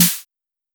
Snare (Tron Cat).wav